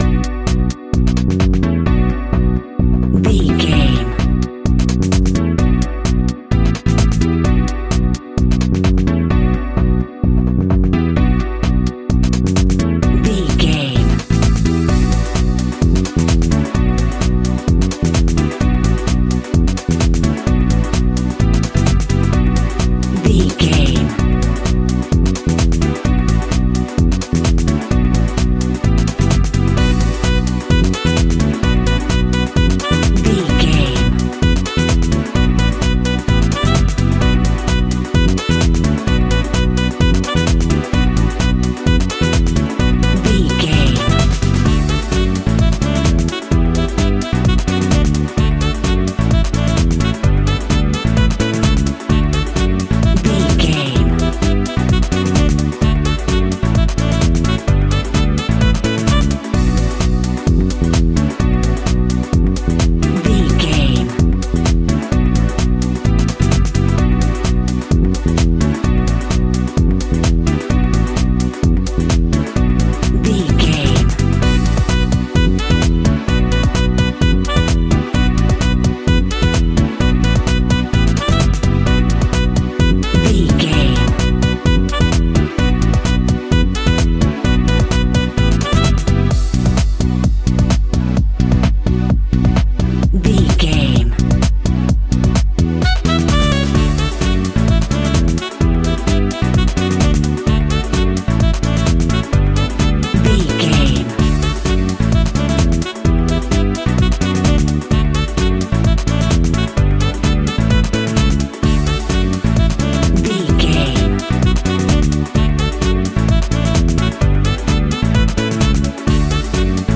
Aeolian/Minor
groovy
uplifting
driving
energetic
bass guitar
drums
strings
saxophone
piano
electric piano
deep house
nu disco
synth
upbeat